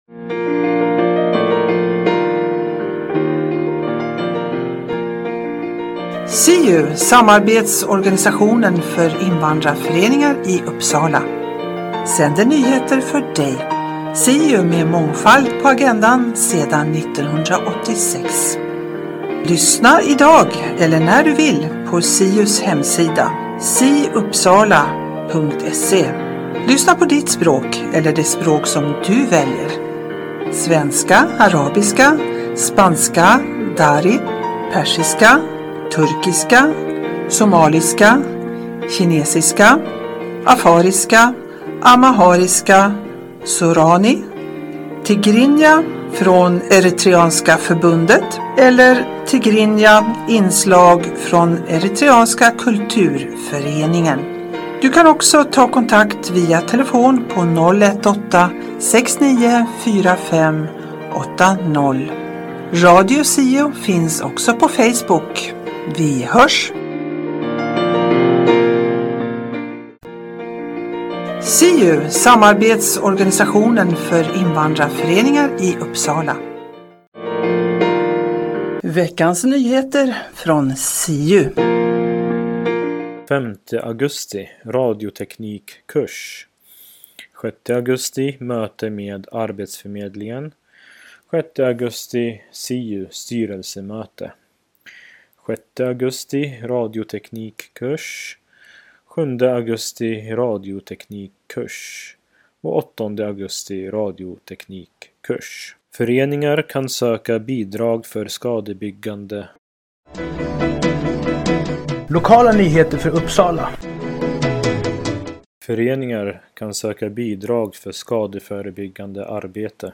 Siu-programmet på svenska innehåller SIU:s nyheter, Nyheter Uppsala och Riksnyheter. Berika din fritid med information och musik.